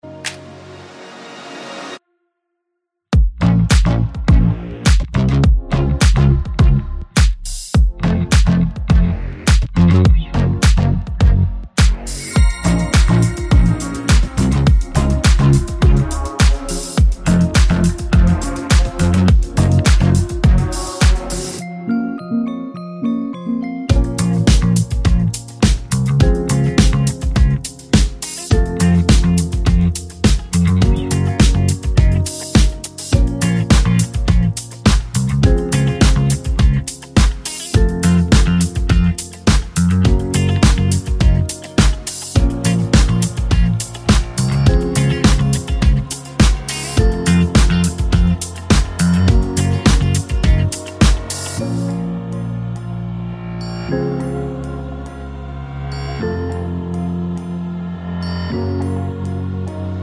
No Backing Vocals with Count In Pop (2020s) 3:38 Buy £1.50